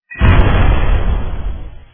use_bomb.wav